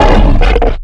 giant_hurt_3.ogg